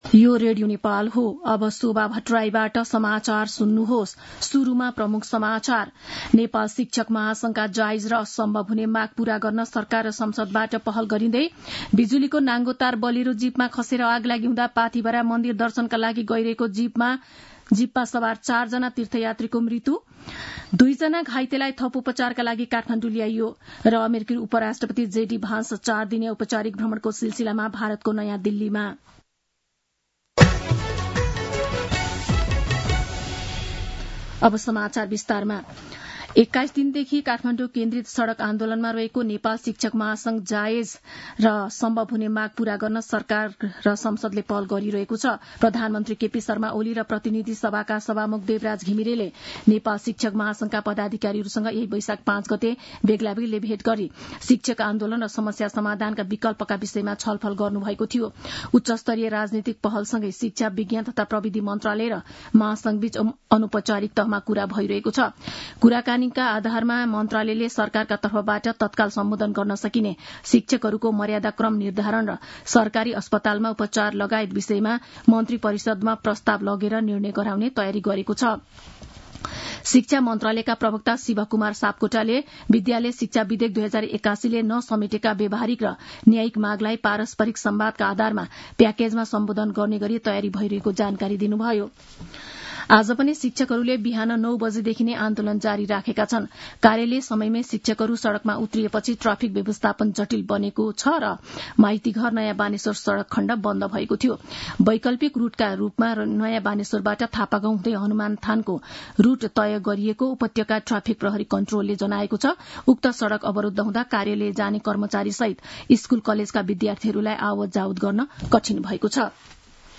दिउँसो ३ बजेको नेपाली समाचार : ८ वैशाख , २०८२
3-pm-news-1-6.mp3